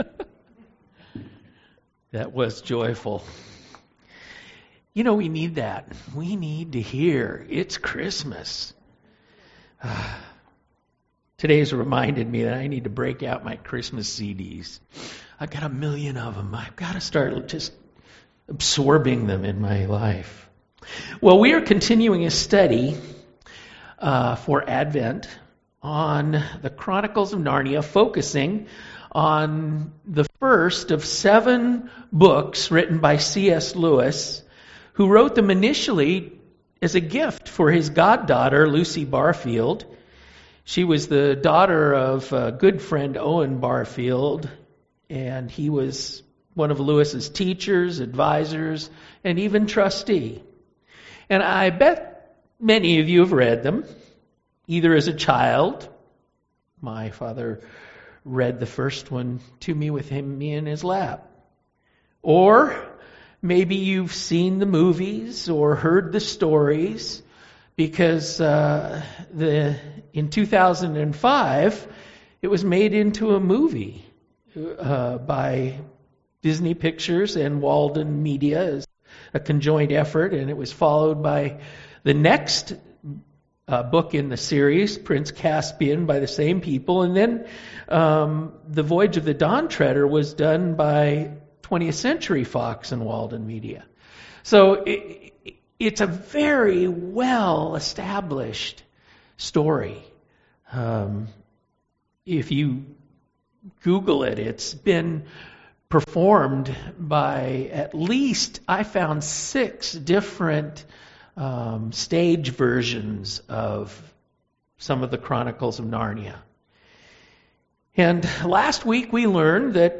Sermon Audio Archives | Church of Newhall